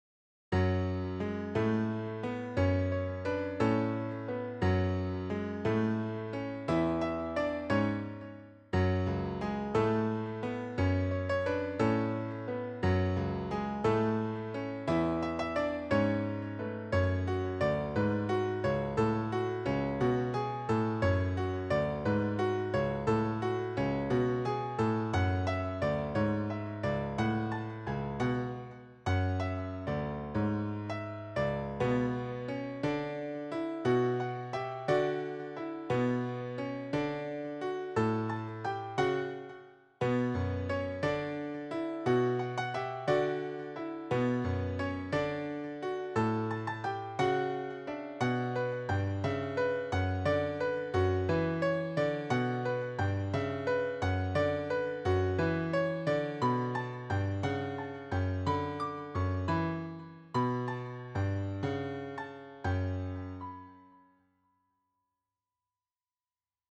This time it's a piano piece, Sequenced in Renoise 1.5 Final (Registered!
I really wanted to work on something slightly classical piano like today, so I'm actually glad that this worked out as planned; here we go:
I aimed for a slightly classical style, and I'm pleased with how it turned out.